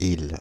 The Isle (French pronunciation: [il]
Fr-Paris--Isle.ogg.mp3